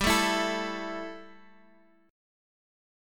A5/G chord